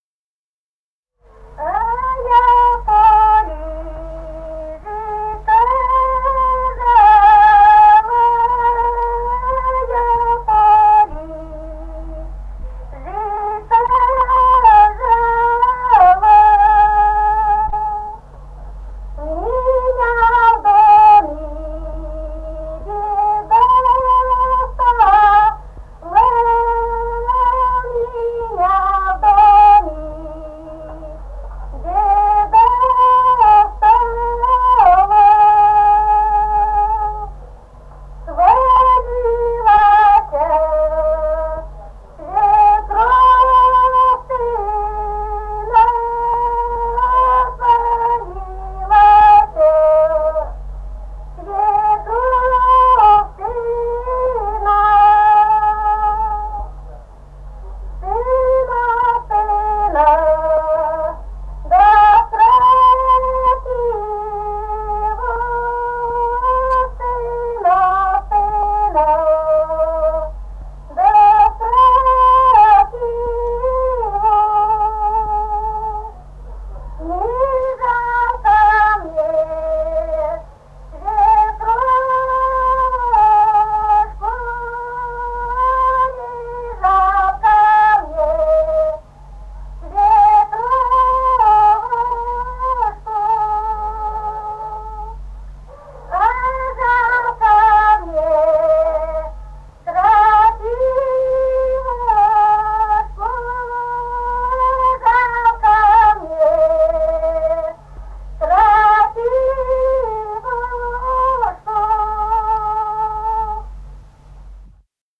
Русские народные песни Красноярского края.
«А я в поле жито жала» (жнивная). с. Тасеево Тасеевского района.